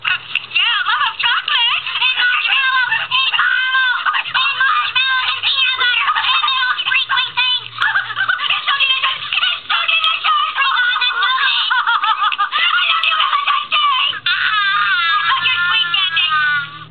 Luv'em Chocolates is a hoops&yoyo greeting card with sound made for valentine's day.
Card sound